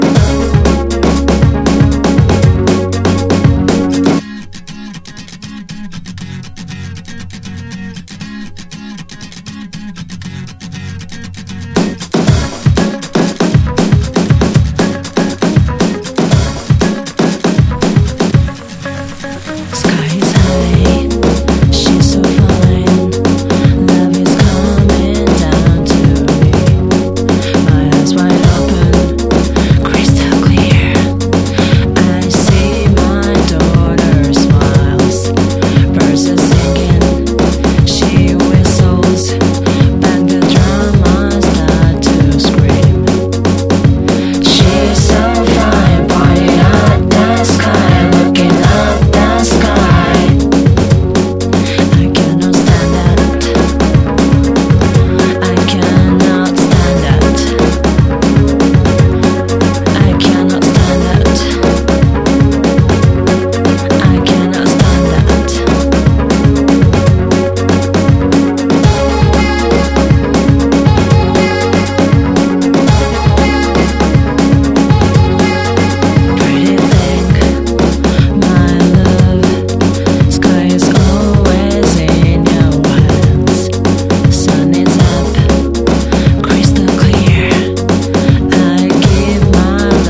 アトモスフェリックなフレーズと引き締まったブレイクビーツ
いつまでも心地よく踊れる色褪せない名曲
ローファイ・ビーツ